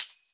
Chart Tamb 01.wav